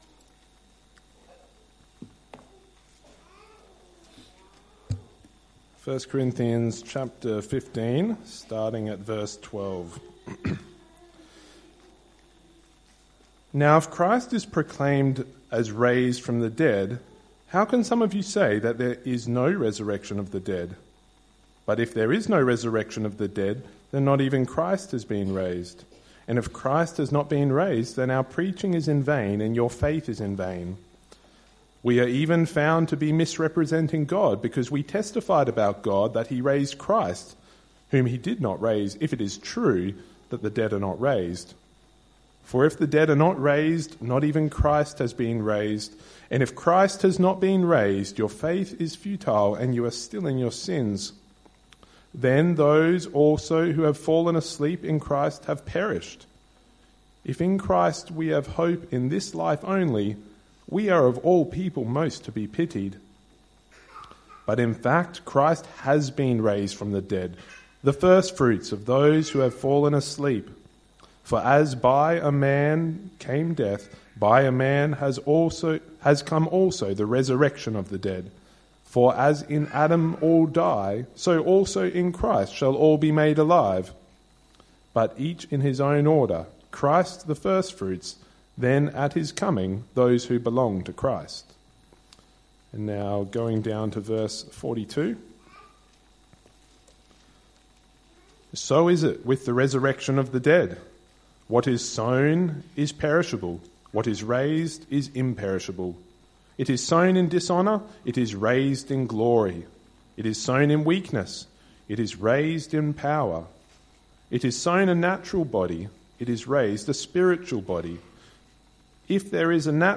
Close Log In using Email Apr 12, 2026 The Resurrection of the Body MP3 SUBSCRIBE on iTunes(Podcast) Notes Evening Service - 12th April 2026 Readings: 1 Corinthians 15:12-23 , 42-49 2 Corinthians 5:1-10